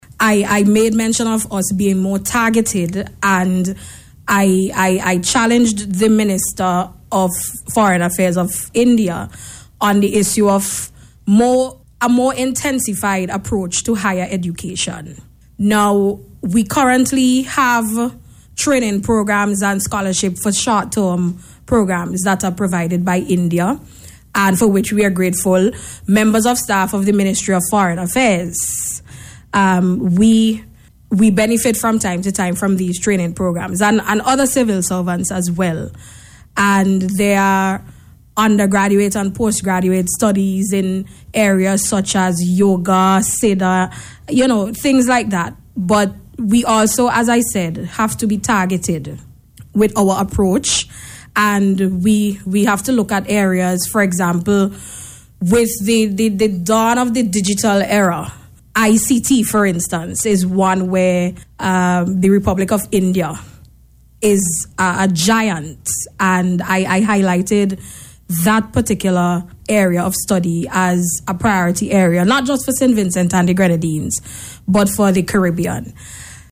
Speaking on radio on Sunday, Minister Peters disclosed that her presentation at the Meeting focused on the issue of Higher Education.